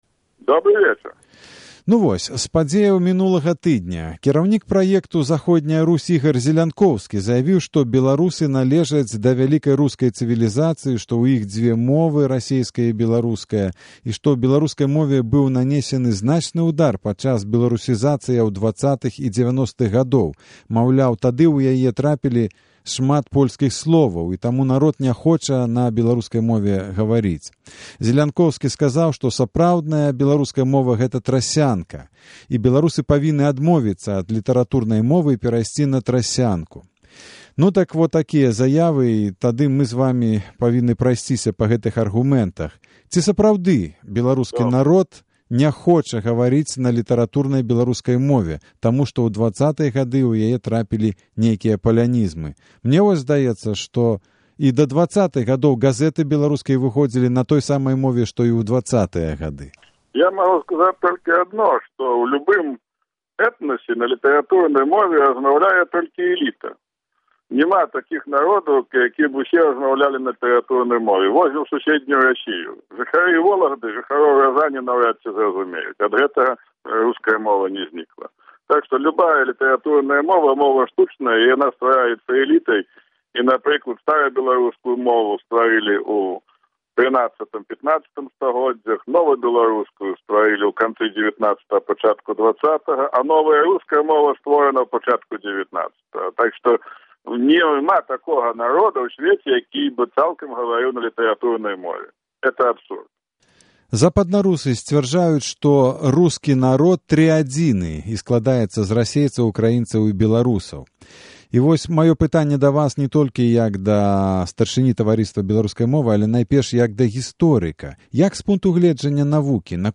Інтэрвію з Алегам Трусавым